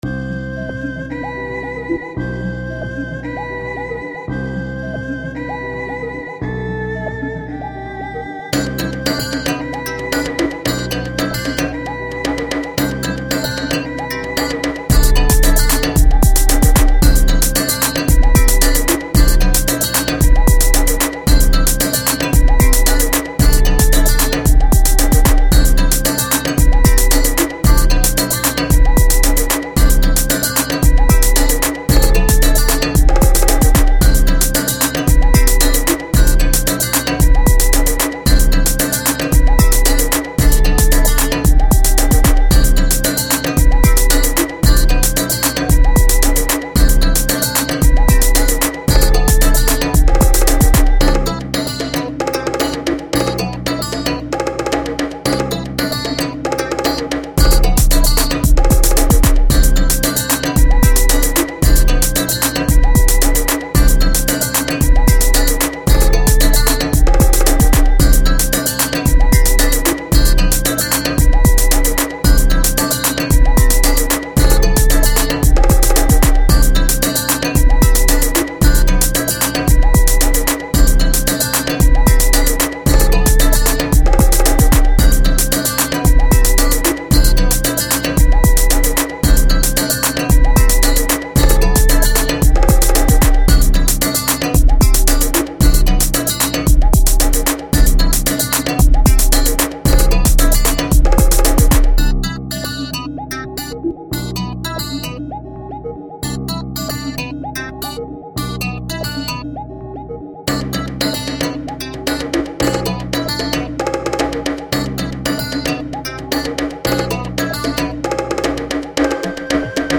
instumental やっぱり中華系のＶＳＴiの音色が主役すぎる。